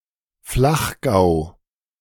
The Bezirk Salzburg-Umgebung (German, "surrounding area of Salzburg") is an administrative district (Bezirk) in the federal state of Salzburg, Austria, and congruent with the Flachgau region (German pronunciation: [ˈflaxˌɡaʊ̯]
De-Flachgau.ogg.mp3